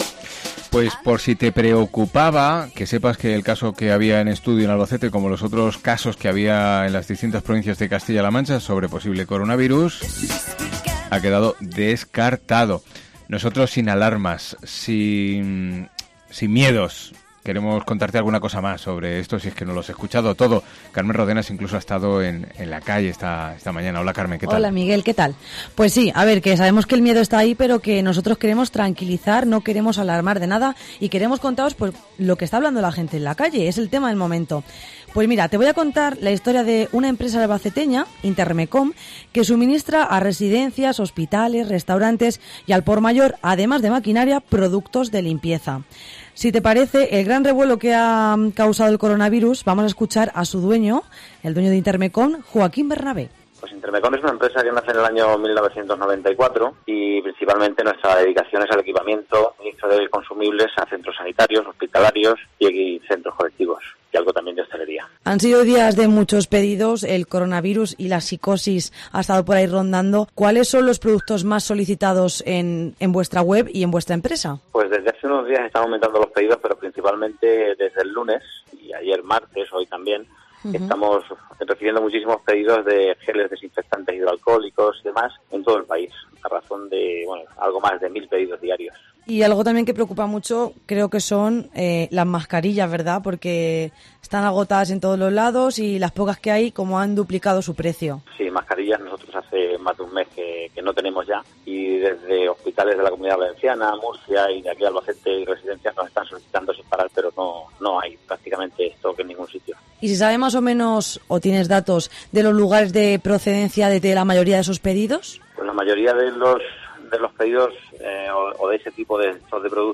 Y por último, no menos importante, qué se sabe y que opinan nuestros oyentes.